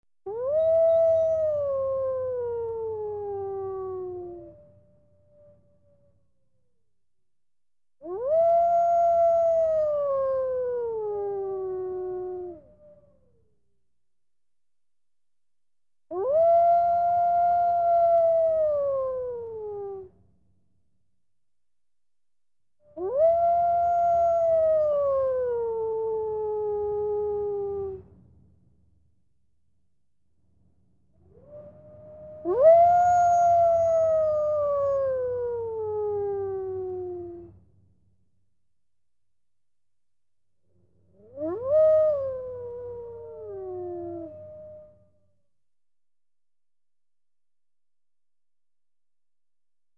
Волчий вой №2
Категория: Голоса волков